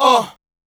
Vox
Wolf Ugh.wav